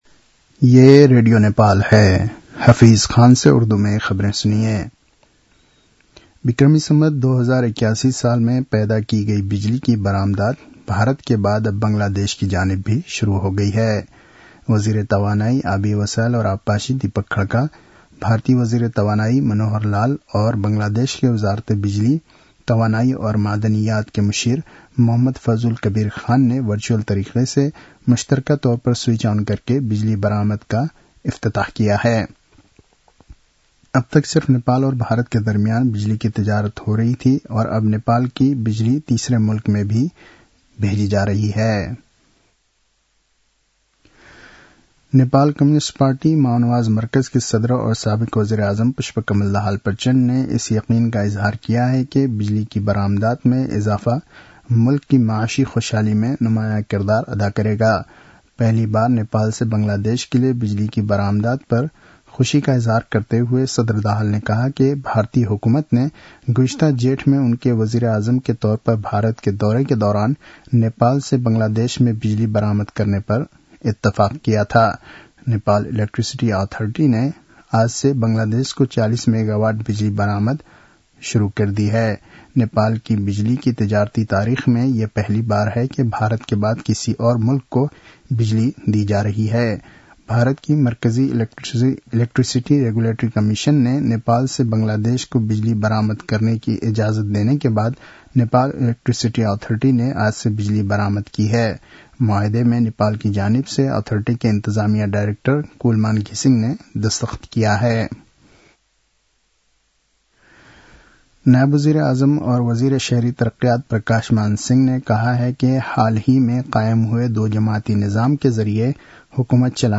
उर्दु भाषामा समाचार : १ मंसिर , २०८१